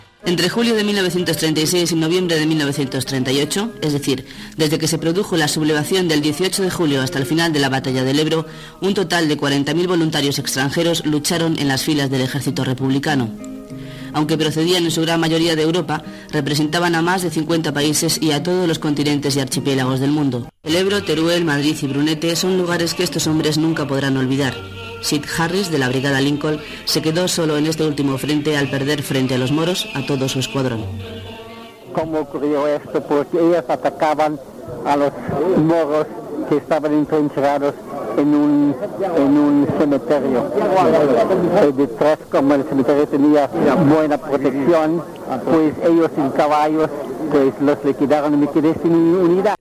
Reportatge dels cinquanta anys de la creació de les Brigades Internacionals.
Paraules de Federica Montseny, la primera dona ministra a l'època de la República espanyola, i records d'un brigadista.
Informatiu